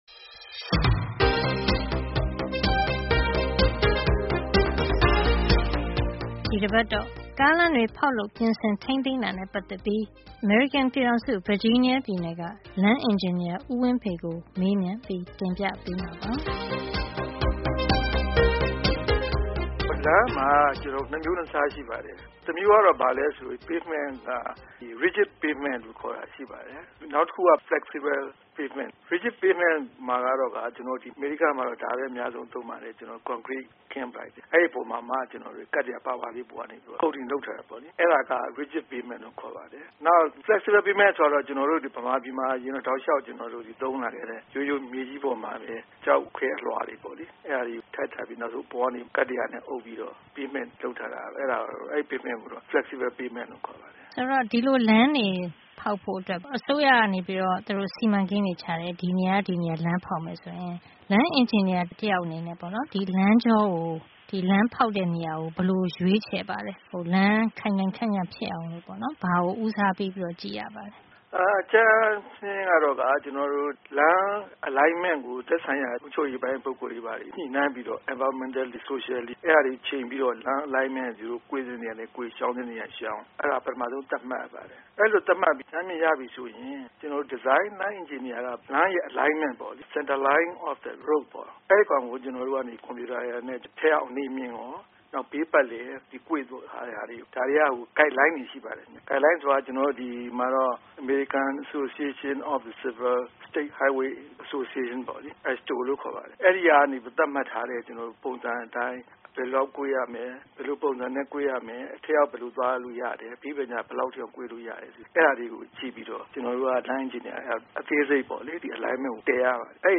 မေးမြန်း တင်ပြထားပါတယ်။